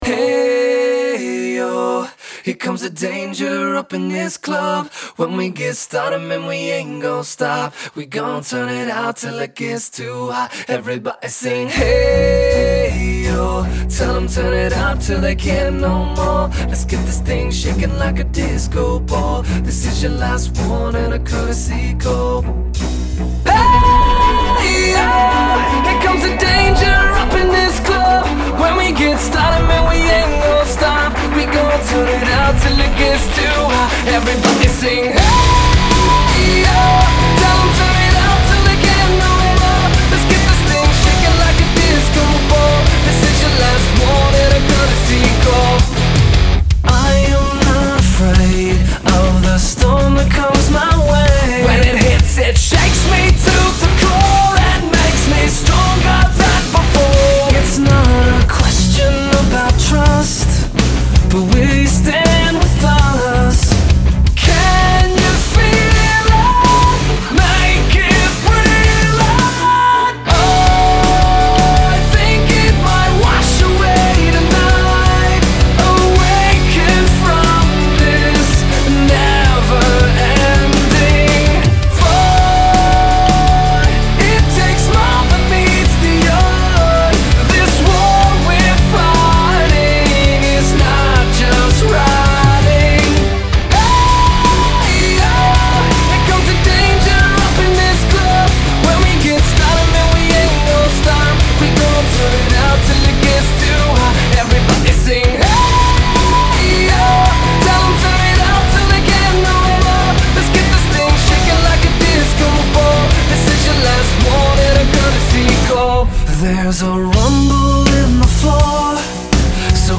Файл в обменнике2 Myзыкa->Зарубежный рок
Жанры: христианский рок, ню-метал,
рэп-метал, рэп-рок, альтернативный рок,
альтернативный метал